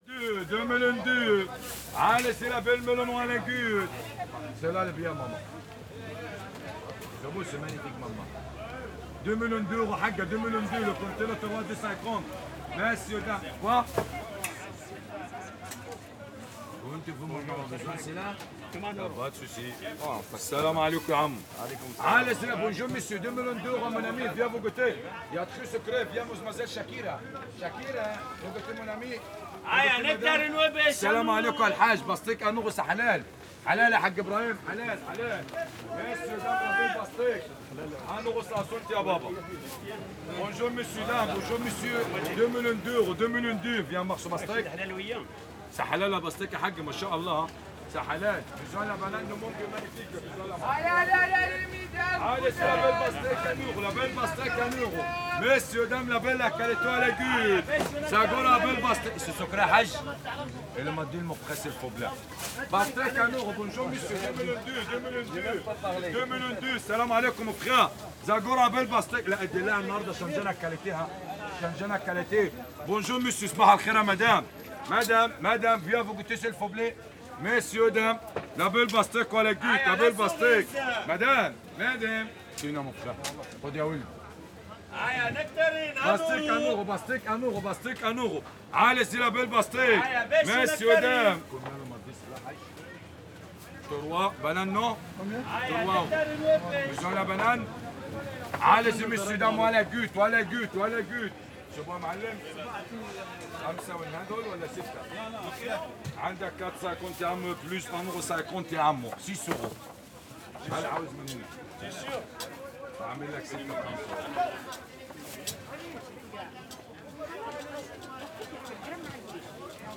Vendeur de fruit et légume (Pastèques, melons, bananes) au marché de Belleville, Boulevard de Belleville, 75020 Paris. Juillet 2020. Prise de son au niveau de vendeur, sur sa gauche
fr CAPTATION SONORE
fr Captation sonore au marché 1
fr Conversation